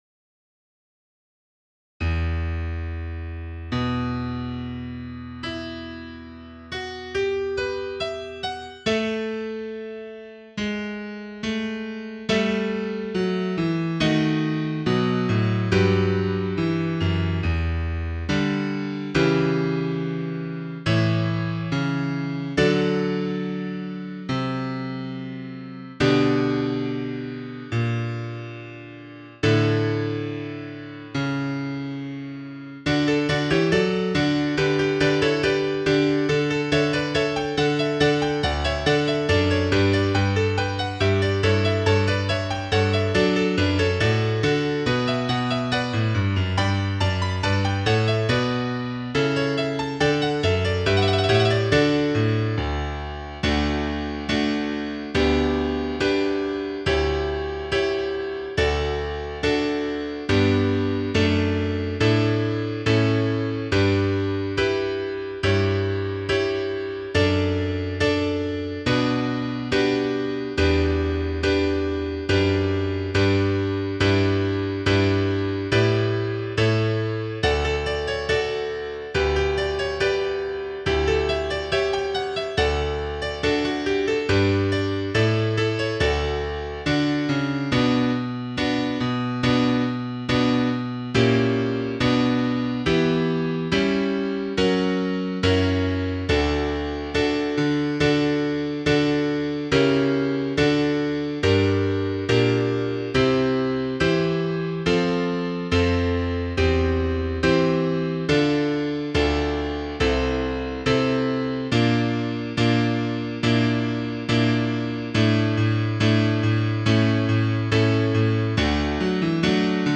Piano
1_Magnificat_anima_mea_Piano.mp3